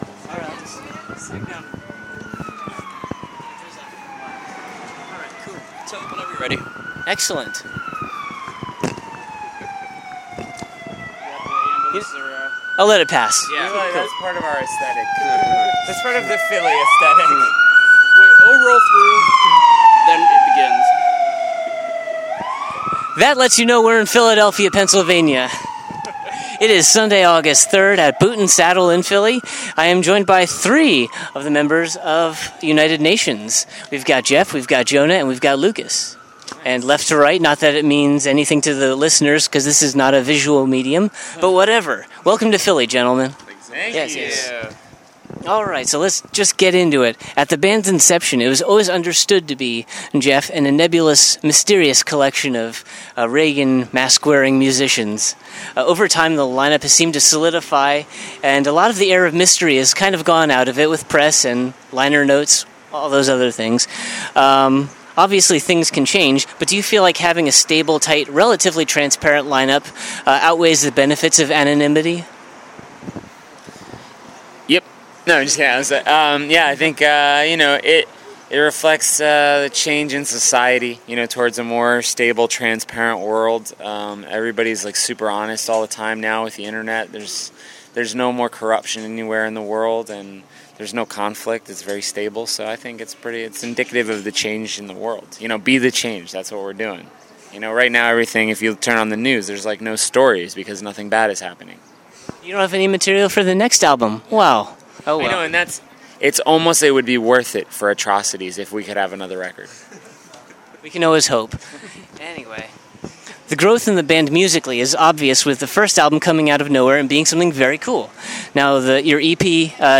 Over the course of a half hour on the mean streets (aka, sidewalk) of Philadelphia outside of the venue ( Boot and Saddle ), we covered a lot of ground. The band indulged my over-researched prodding about the benefits of a stable lineup at the expense of their anonymity, some details about the recording of their new album The Next Four Years (IN STORES NOW), settling in with Temporary Residence Records , and the myriad endeavors that they are doing individually.
53-interview-united-nations.mp3